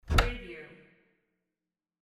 Closet Door Open Wav Sound Effect #2
Description: The sound of a closet door pulled open
Properties: 48.000 kHz 16-bit Stereo
Keywords: closet, door, open, opening, roller, catch
closet-door-open-preview-2.mp3